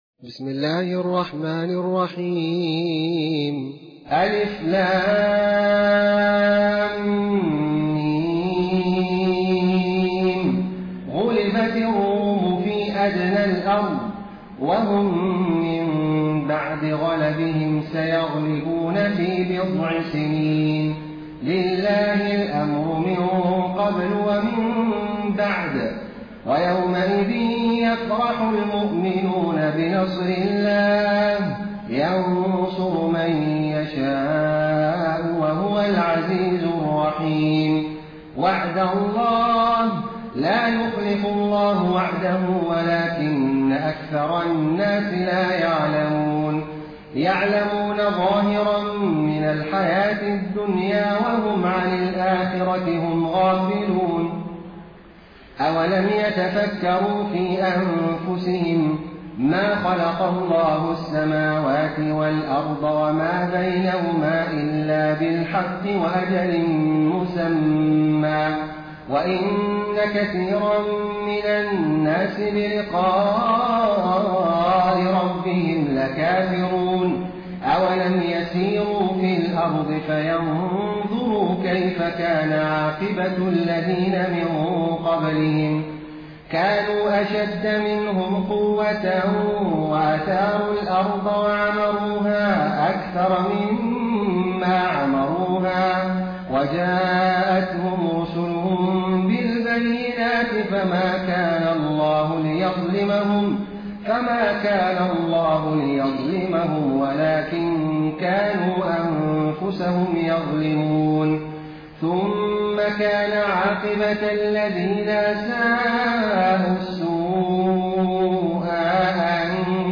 Quran recitations
taraweeh-1433-madina